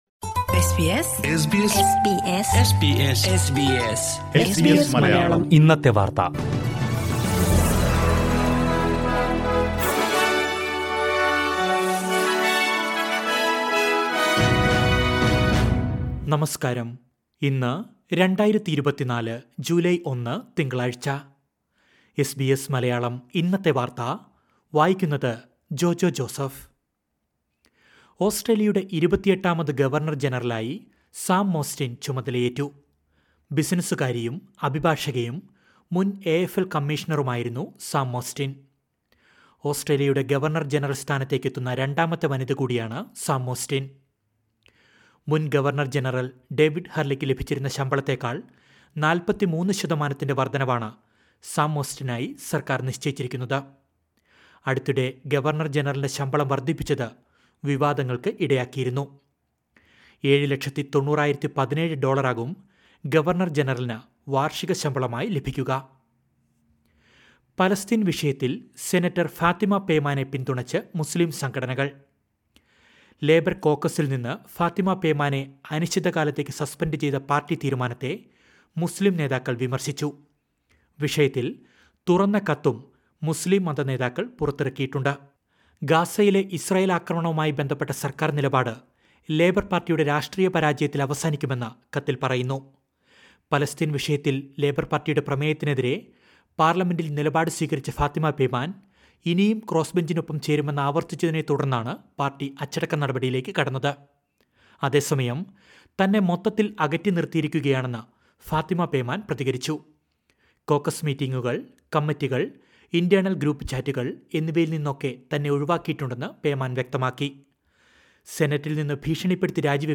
2024 ജൂലൈ 1ലെ ഓസ്‌ട്രേലിയയിലെ ഏറ്റവും പ്രധാന വാര്‍ത്തകള്‍ കേള്‍ക്കാം...